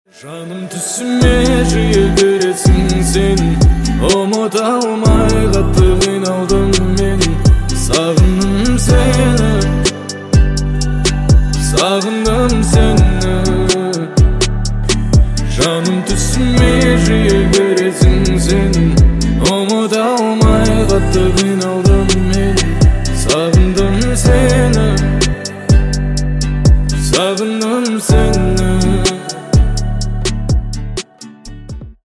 Красивый мужской голос Казахские
Романтические